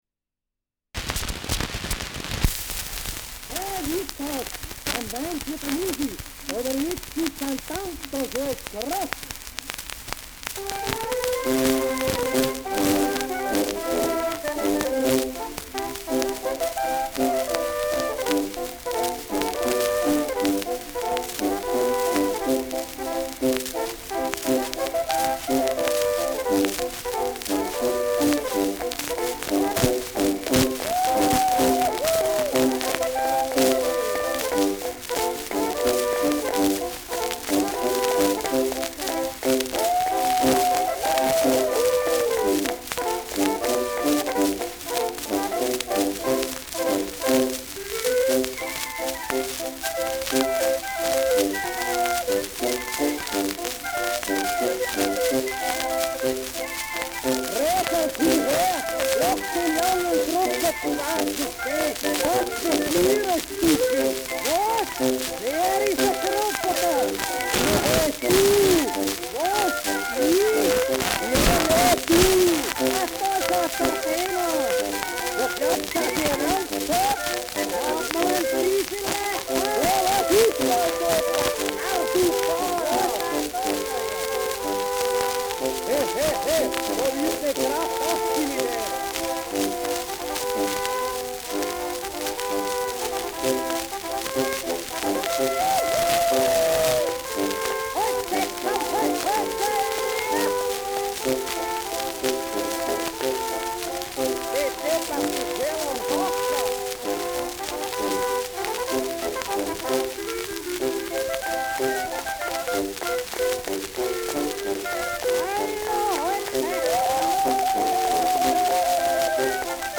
Schellackplatte
Tonrille: Kratzer Durchgehend Leicht
Starkes Grundknistern : Gelegentlich starkes Zischen : gelegentlich leichtes bis stärkeres Knacken
Bauernkapelle Salzburger Alpinia (Interpretation)
Gesprochene Einleitung.